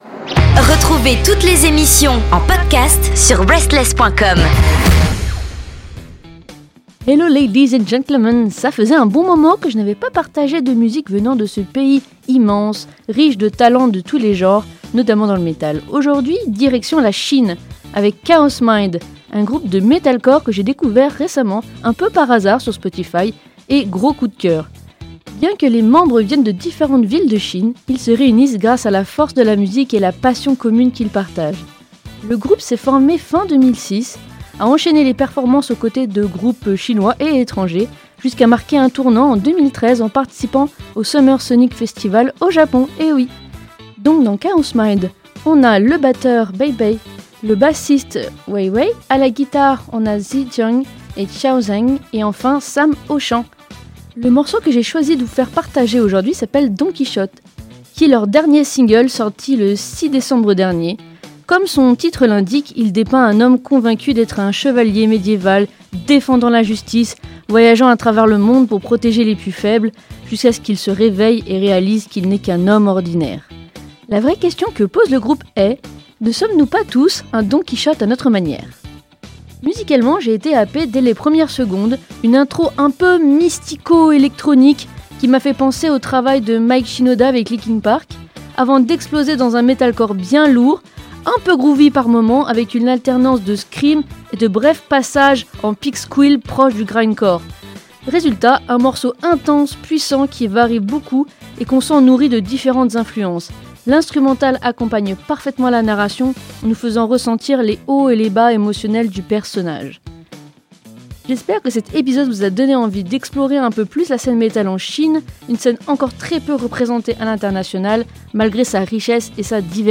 En totale exclusivité (comme souvent sur ce podcast), je vous ai déniché un sacré groupe de metalcore, aux influences ultra variées : des guitares de folie, des screams puissants agrémentés de pigsqueals bien placés…